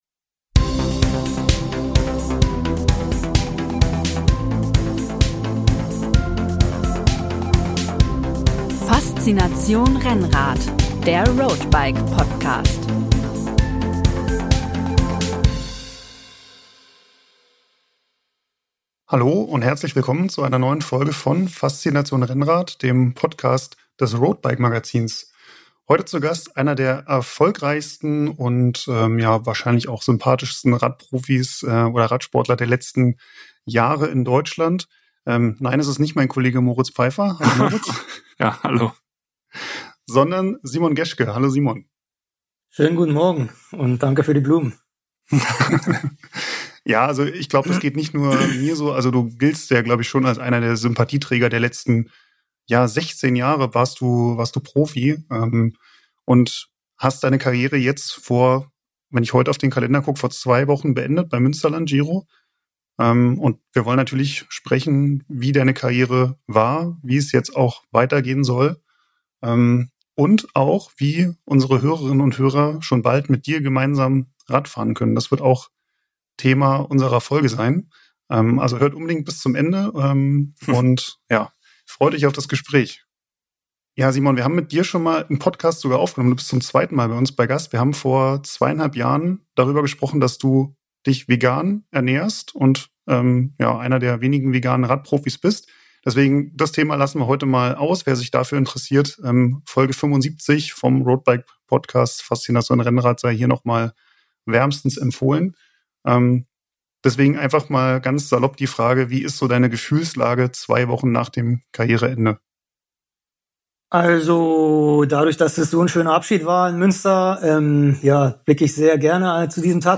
Simon Geschke im Interview